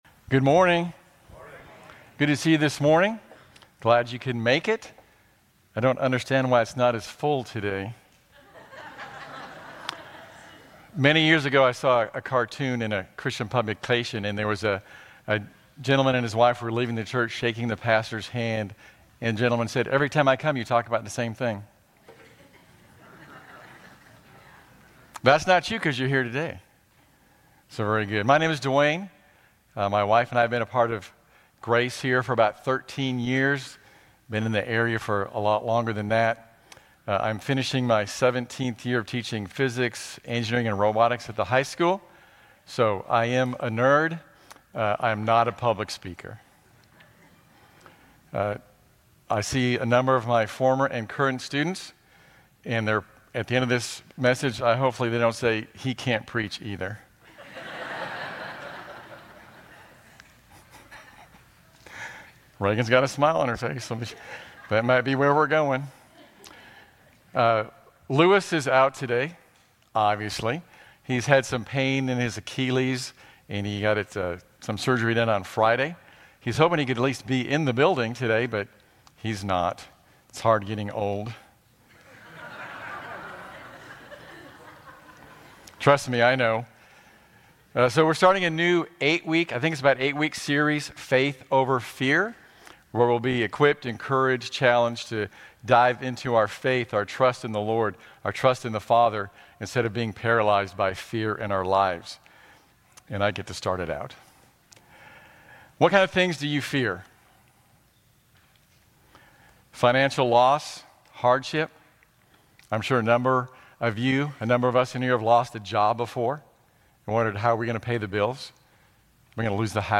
Grace Community Church Lindale Campus Sermons 4_27 Lindale Campus Apr 27 2025 | 00:32:44 1x 00:00 / 00:32:44 Subscribe Share RSS Feed Share Link Embed